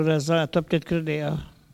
Soullans
collecte de locutions vernaculaires